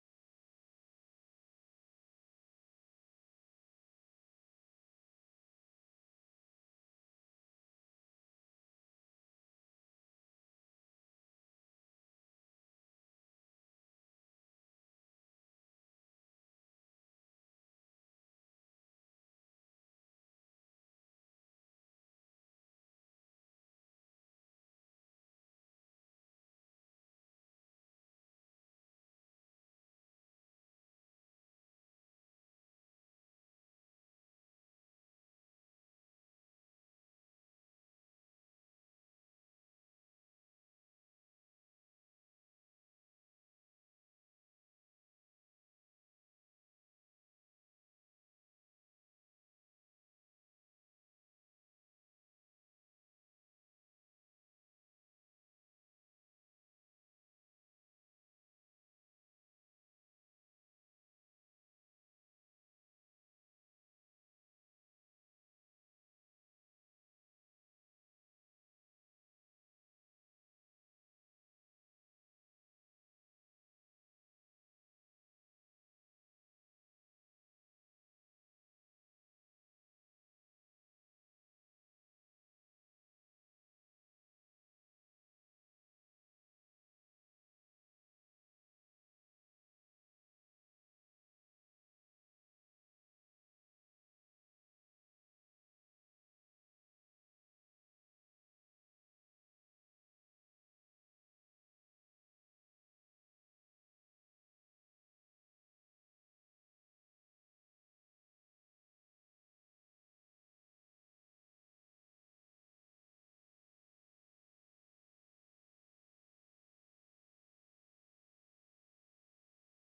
Laporan berita padat dan ringkas